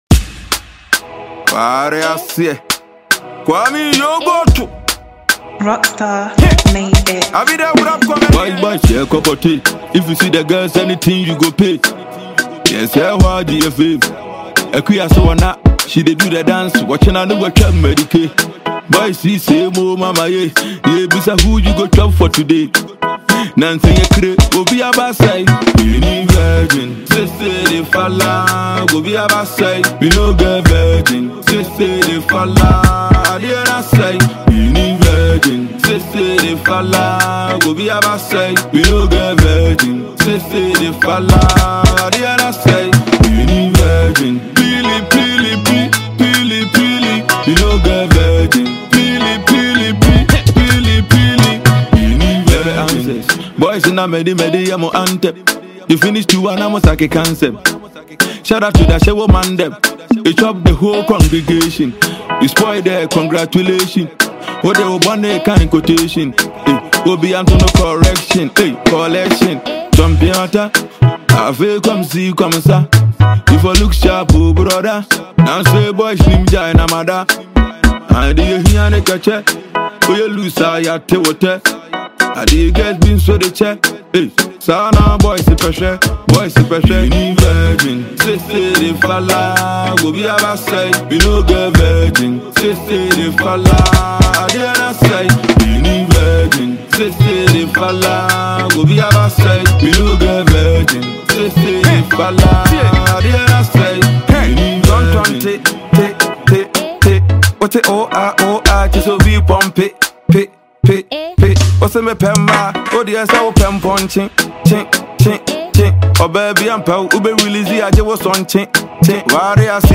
Ghanaian rapper
banging tune
The new catchy single